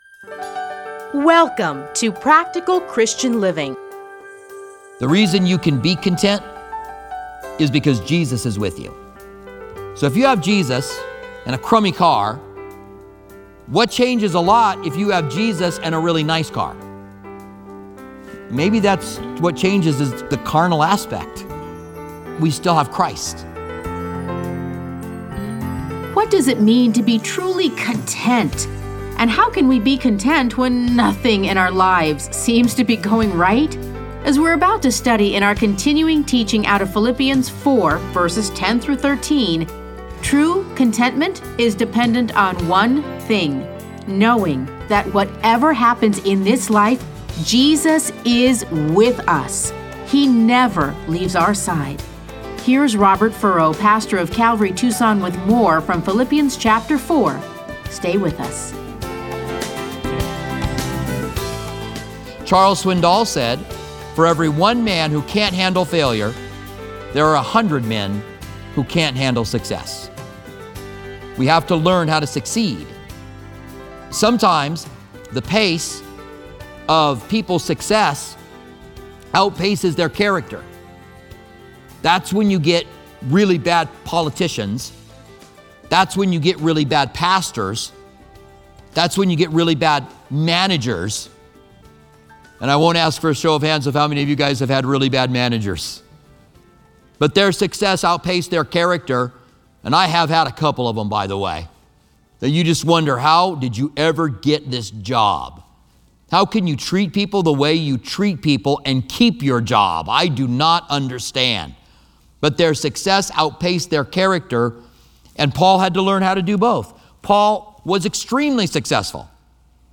Listen to a teaching from A Study in Philippians 4:10-13.